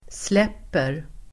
Uttal: [sl'ep:er]